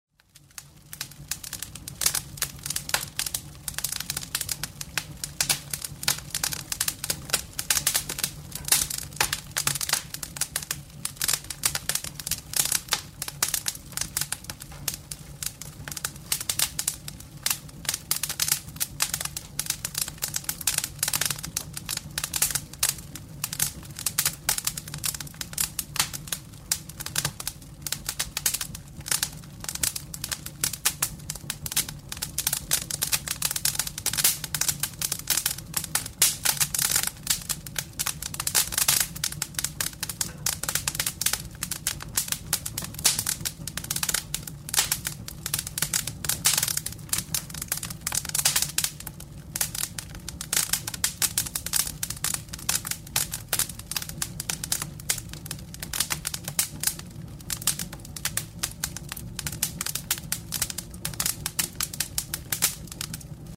На этой странице собраны натуральные звуки обогревателей и каминов: от монотонного гула масляного радиатора до живого потрескивания дров в очаге.
Звук потрескивающих дров в камине